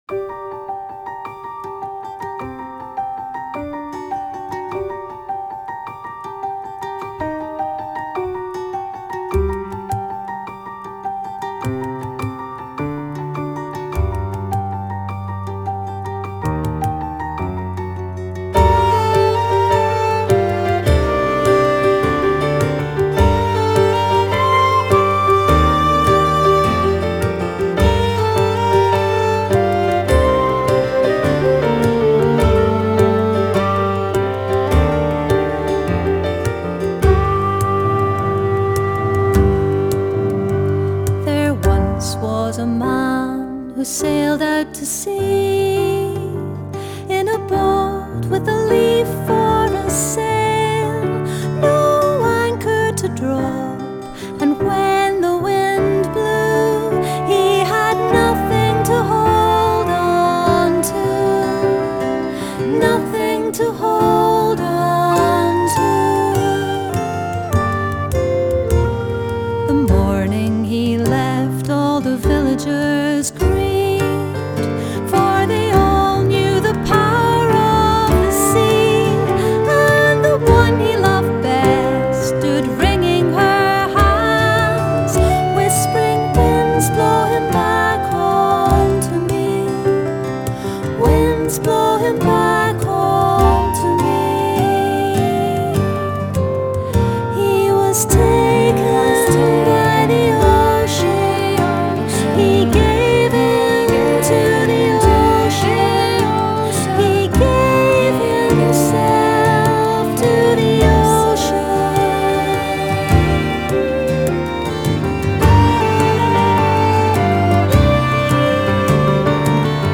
Genre: World, Folk, Celtic, Contemporary Celtic